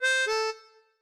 melodica_c1a.ogg